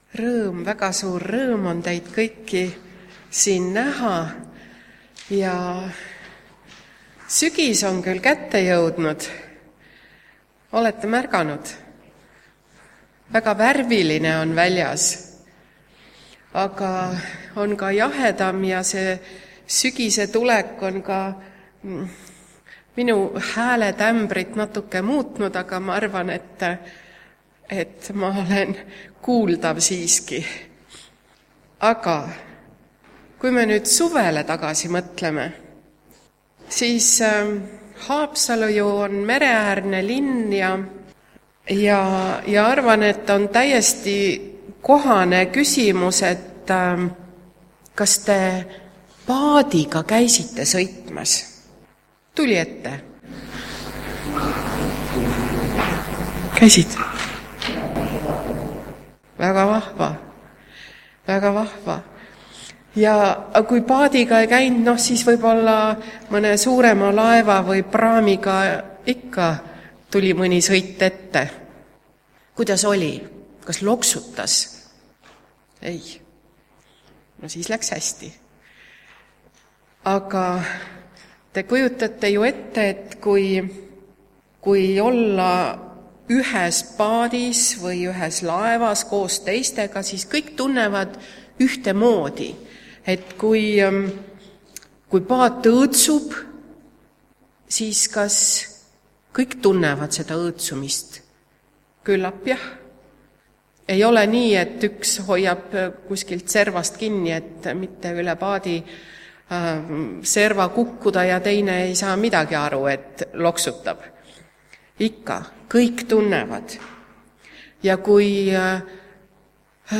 Tänase jutluse teema on olemine ÜHES PAADIS Lapsed laulavad ka laulu "Me Jumal on suur"
Jutlused